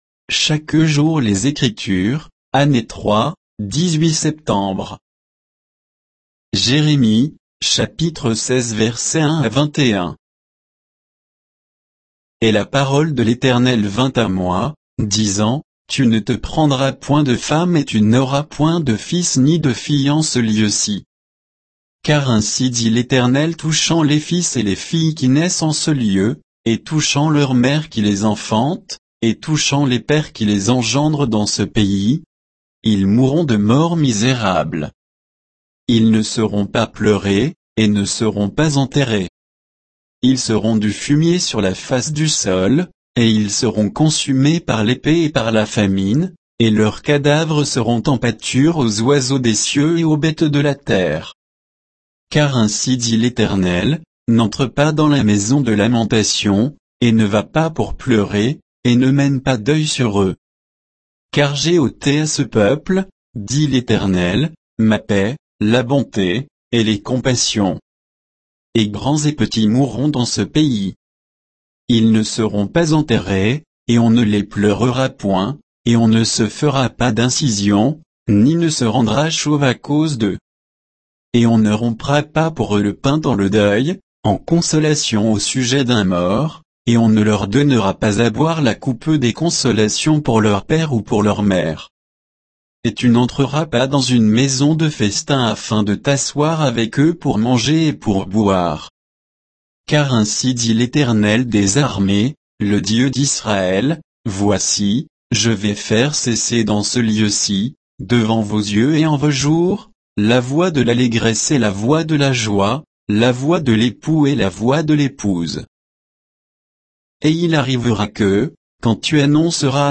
Méditation quoditienne de Chaque jour les Écritures sur Jérémie 16